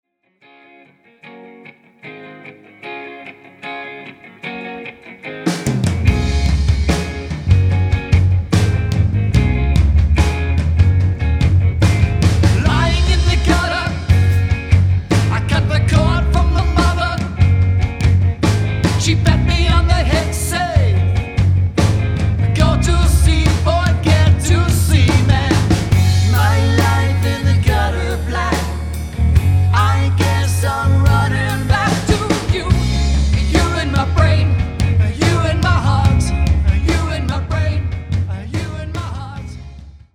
dynamic 4-piece rock/dance band
Hawke’s Bay Cover Band
SAMPLE SONGLIST